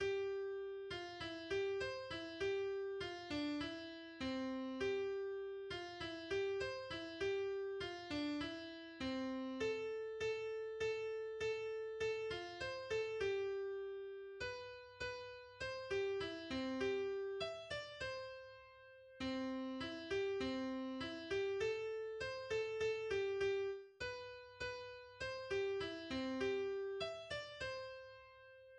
deutsches Volkslied
Melodie